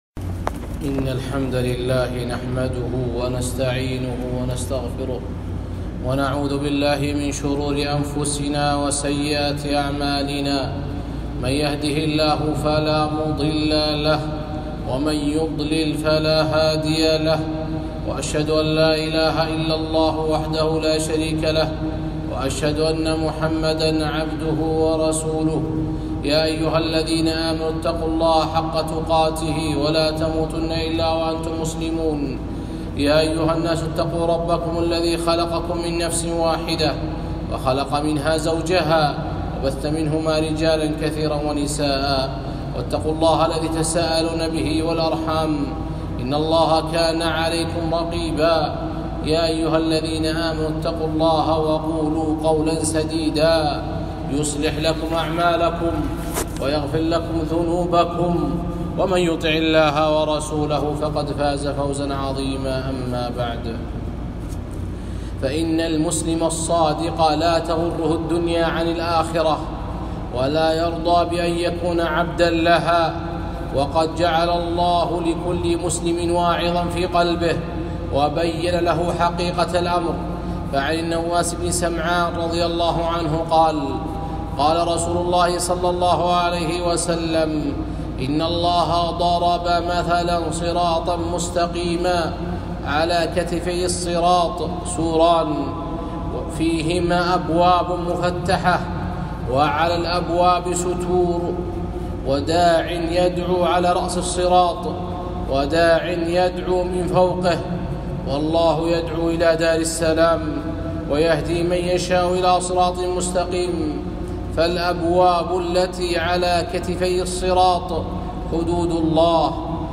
خطبة - ثلاثٌ يُدرِك بهن العبد رغائب الدنيا والآخرة